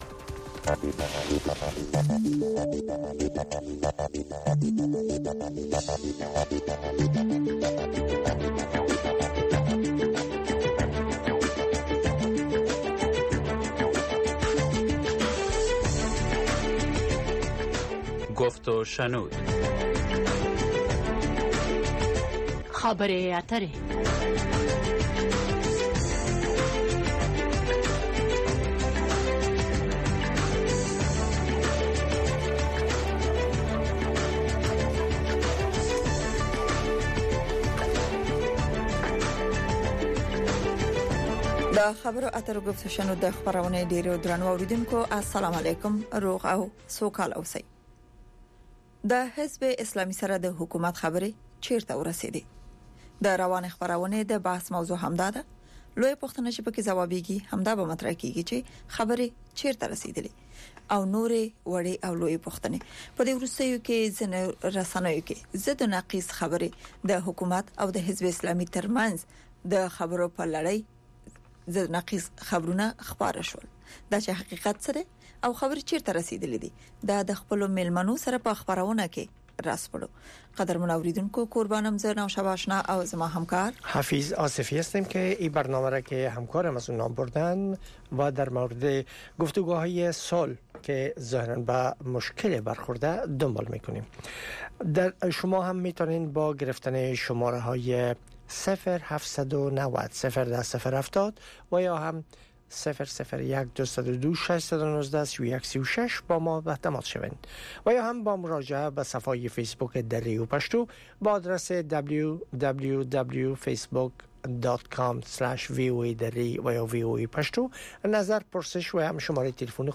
گفت و شنود - خبرې اترې، بحث رادیویی در ساعت ۸ شب به وقت افغانستان به زبان های دری و پشتو است. در این برنامه، موضوعات مهم خبری هفته با حضور تحلیلگران و مقام های حکومت افغانستان بحث می شود.